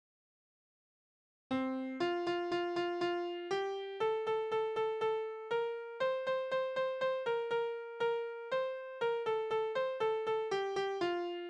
Kinderspiele: Arbeiten
Tonart: F-Dur
Taktart: 4/4
Tonumfang: Oktave